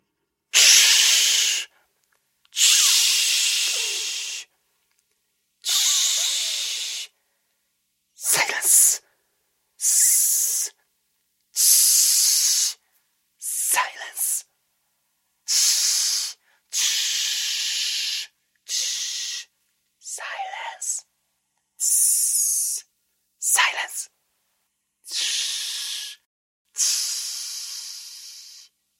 Звуки тс-с
Звук: тсс, тише там (шипение человека)